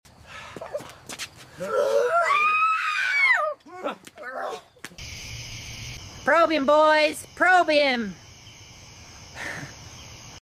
Caught on Ring Cam at 2AM You won’t believe what these tiny weirdos tried to do to this poor guy at 2AM. Yes, that’s right — they really yelled “PROBE HIM” 😭 Someone come get Zorb.